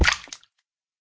land_hit2.ogg